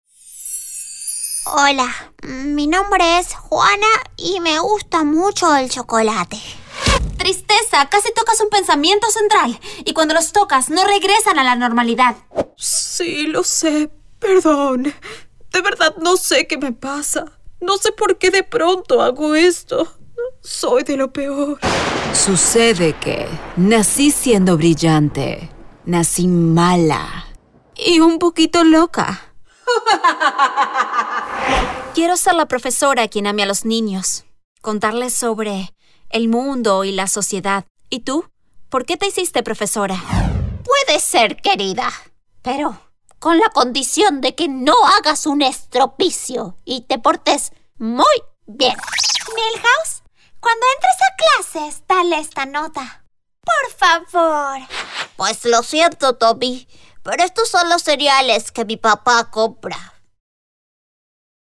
Espanhol - América Latina Neutro
Demo Personajes Español Neutro Latinoamericano
Voz Infantil 01:01
Spanish professional Voice Artist with recording booth.